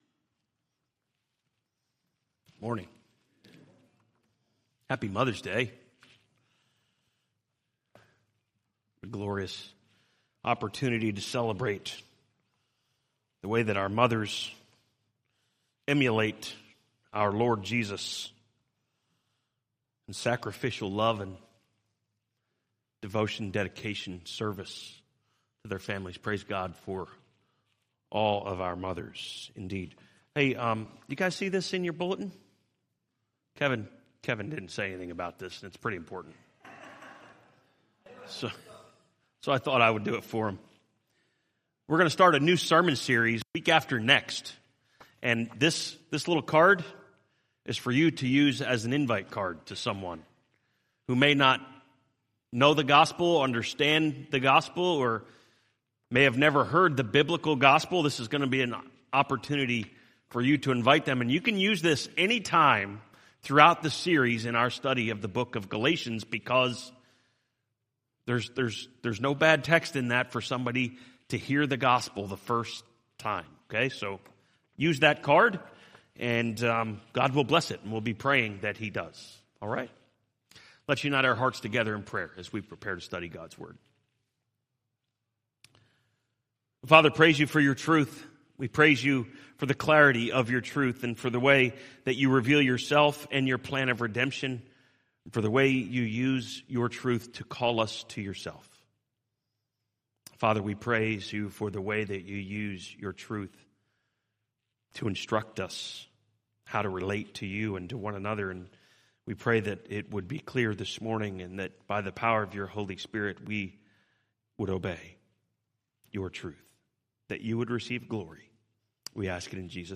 From Series: "2023 Sermons"